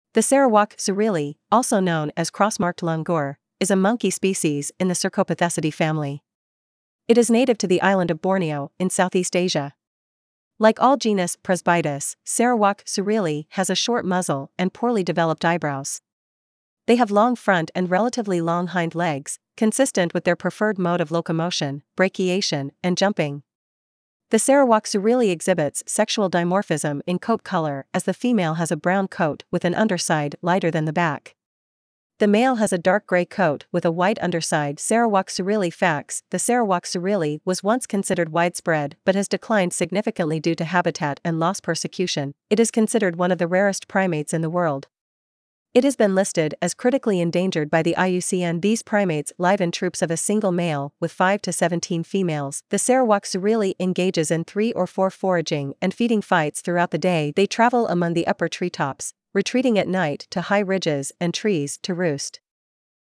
Cross Marked Langur
Cross-marked-Langur.mp3